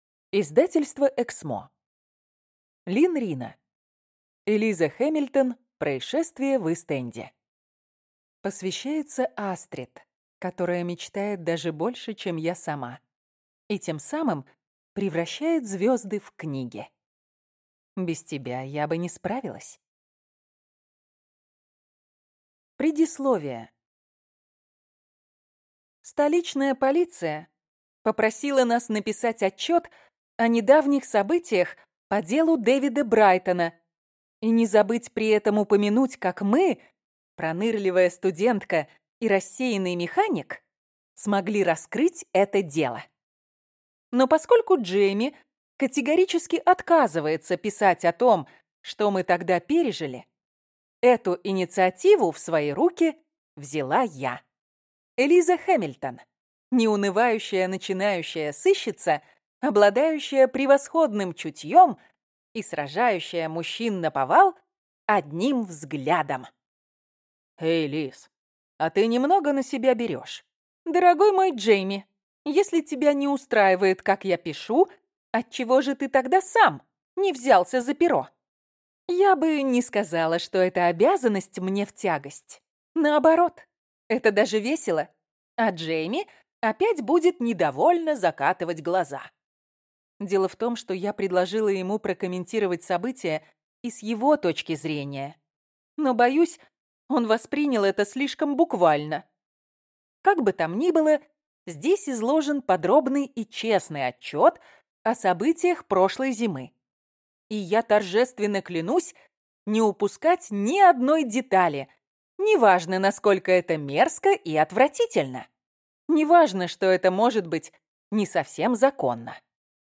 Аудиокнига Элиза Хеммильтон. Происшествие в Ист-Энде | Библиотека аудиокниг